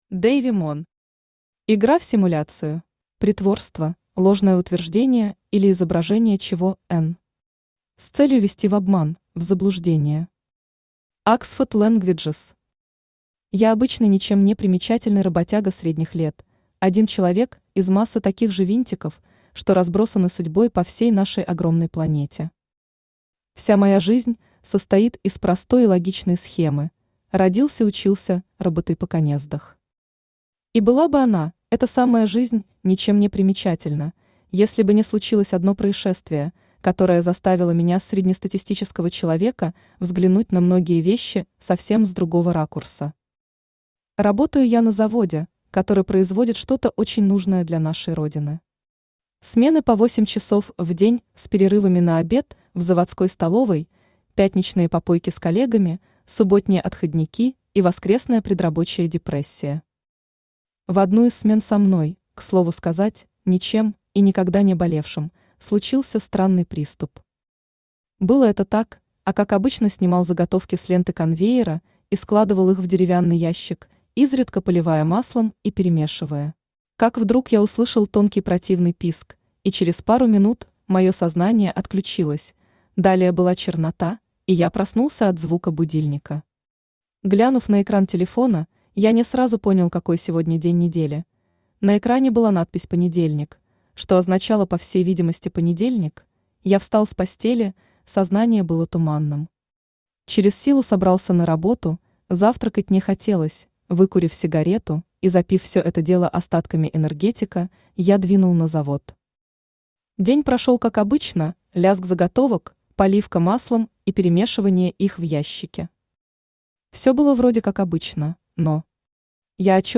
Аудиокнига Игра в симуляцию | Библиотека аудиокниг
Aудиокнига Игра в симуляцию Автор Дейви Мон Читает аудиокнигу Авточтец ЛитРес.